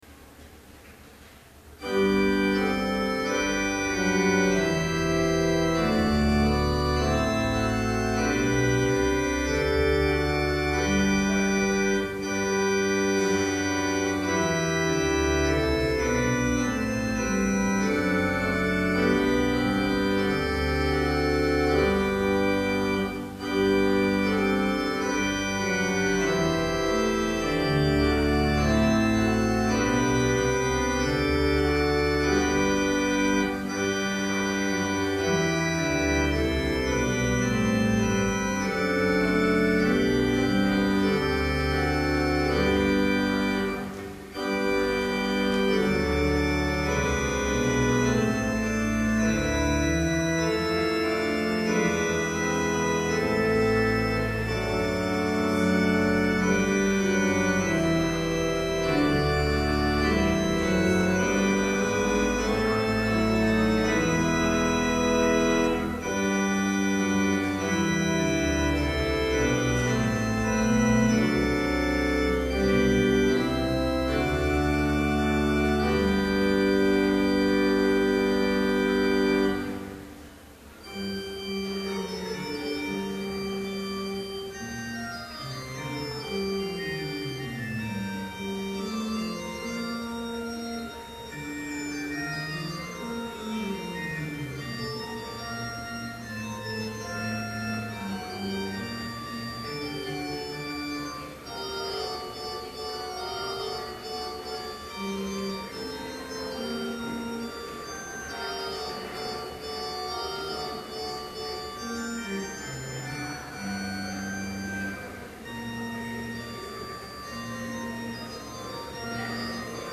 Complete service audio for Chapel - January 24, 2012